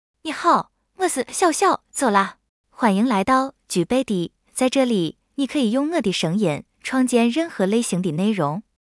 Xiaoxiao DialectsFemale Chinese AI voice
Xiaoxiao Dialects is a female AI voice for Chinese (Mandarin, Simplified).
Voice: Xiaoxiao DialectsGender: FemaleLanguage: Chinese (Mandarin, Simplified)ID: xiaoxiao-dialects-zh-cn
Voice sample
Listen to Xiaoxiao Dialects's female Chinese voice.